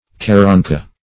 Search Result for " carrancha" : The Collaborative International Dictionary of English v.0.48: Carrancha \Car*ran"cha\, n. [Native name.]